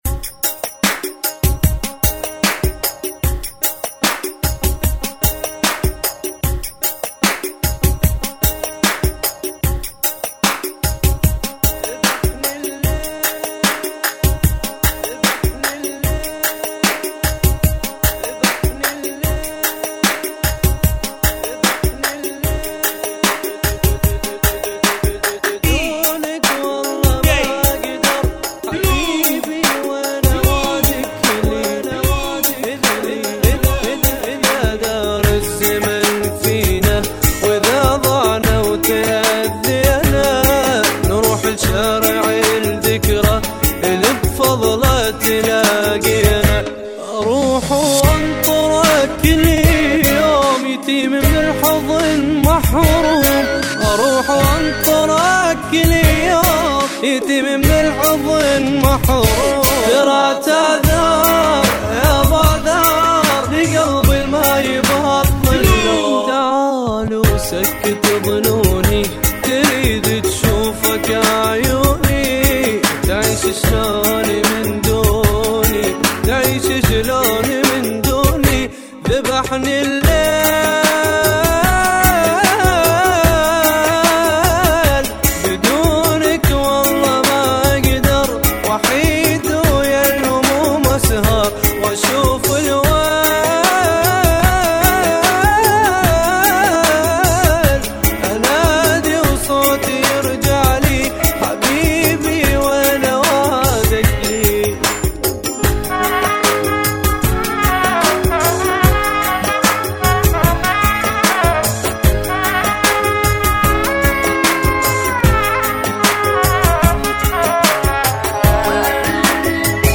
[ 75 Bpm ]